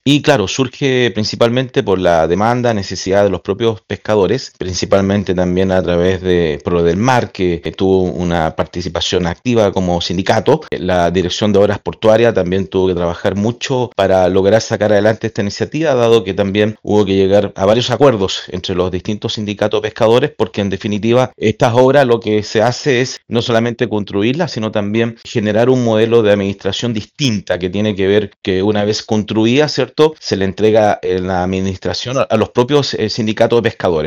Según explicó la autoridad comunal, el proyecto tiene más de siete años de historia, y se gestó a partir de las demandas de los propios pescadores, quienes participaron activamente a través de sus organizaciones.